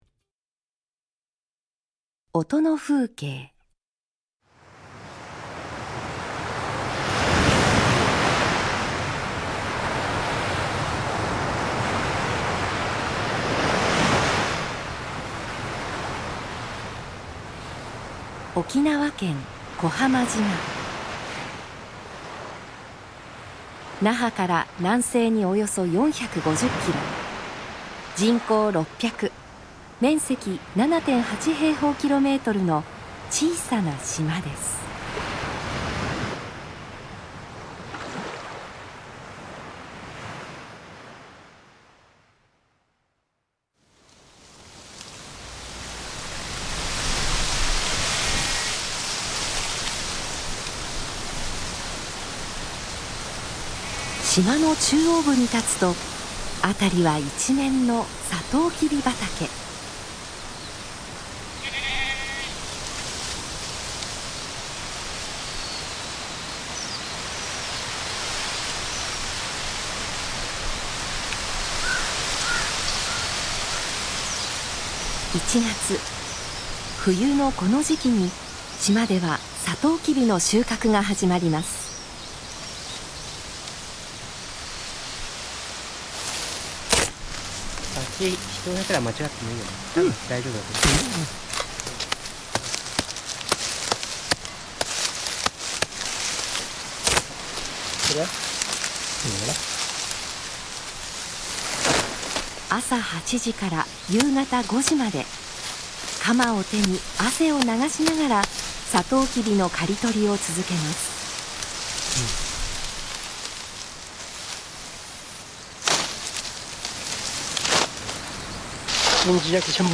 サトウキビの収穫～沖縄～として紹介されました。